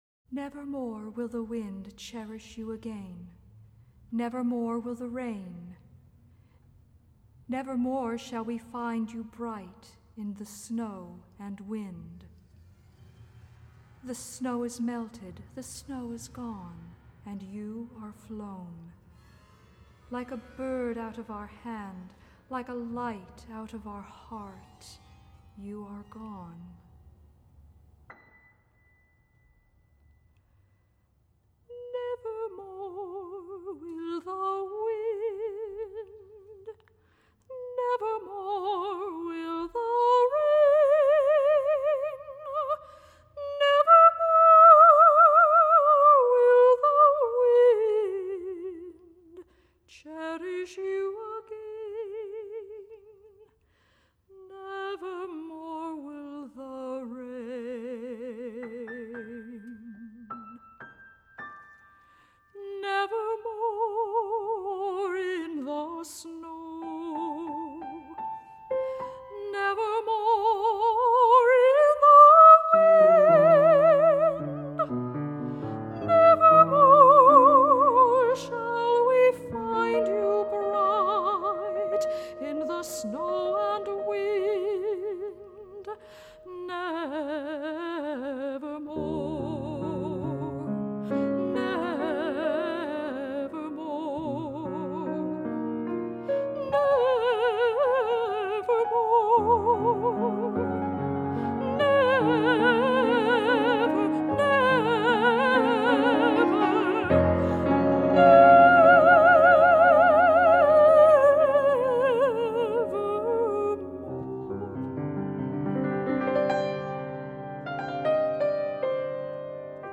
for Soprano and Piano (1982)